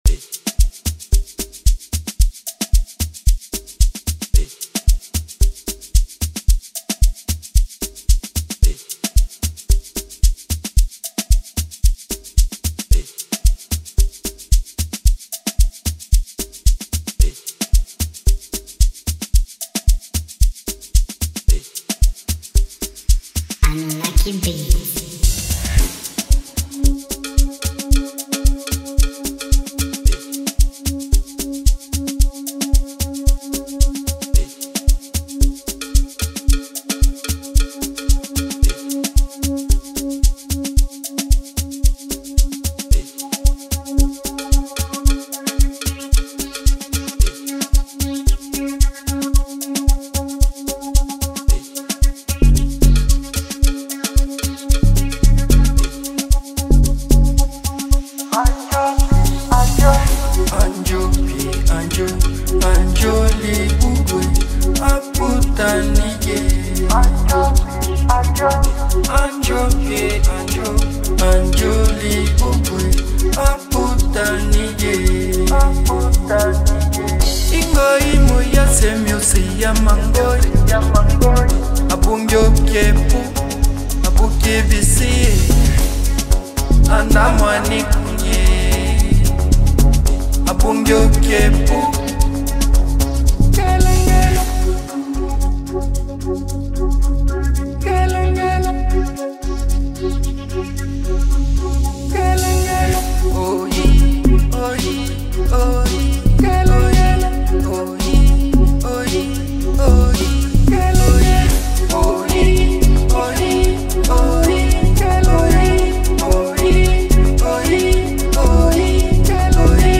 They both bring their A-game with some really hot verses.